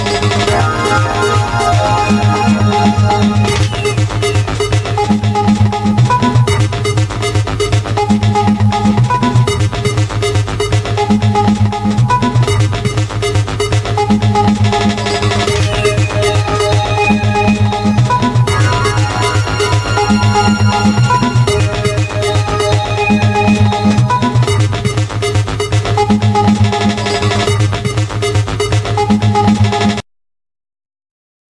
Générique audio